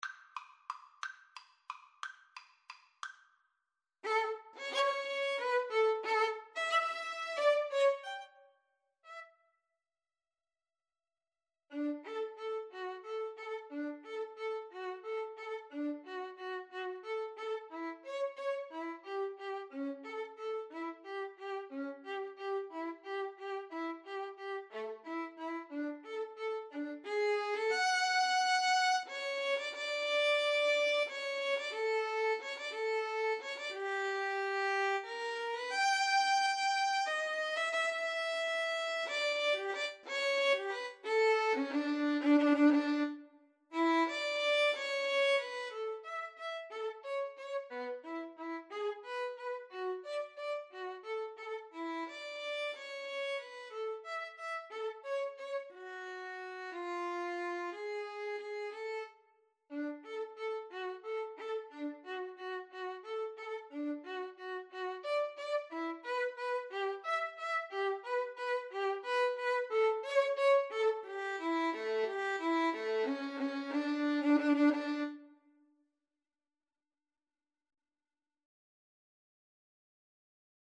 3/4 (View more 3/4 Music)
~ = 180 Tempo di Valse
D major (Sounding Pitch) (View more D major Music for Violin Duet )